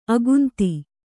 ♪ agunti